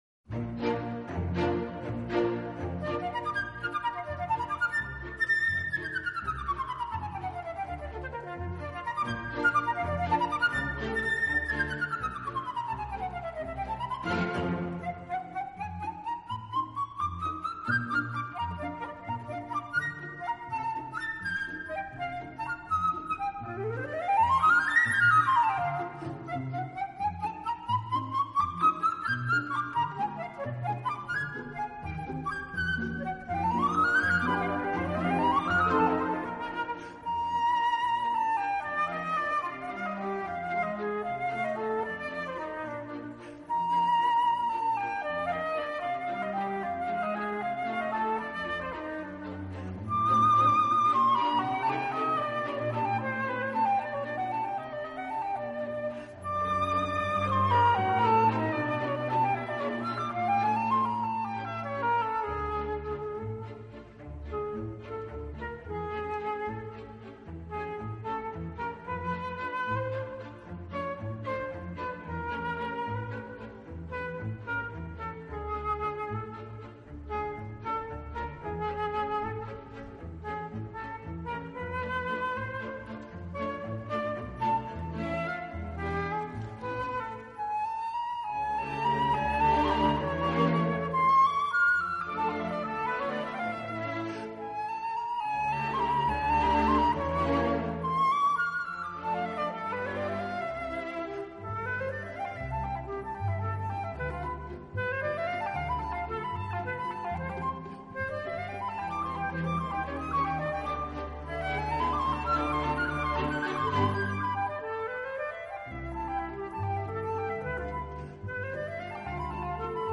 【长笛】
【新世纪长笛】